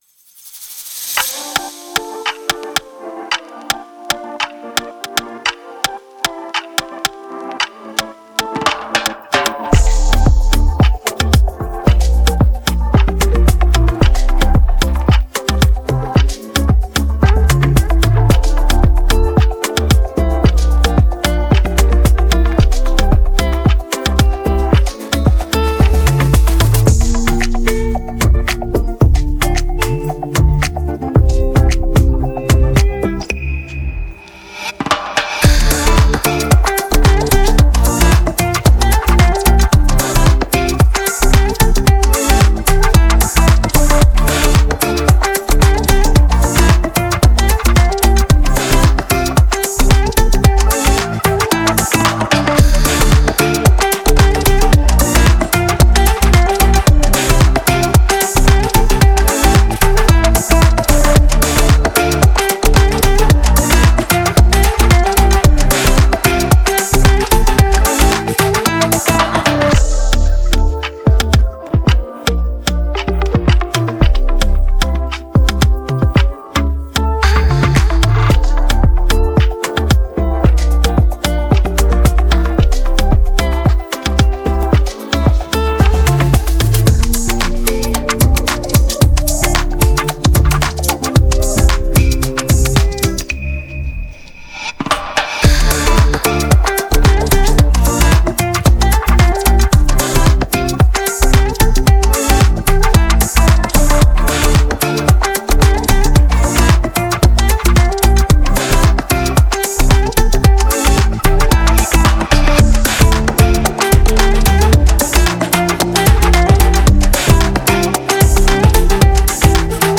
(минус)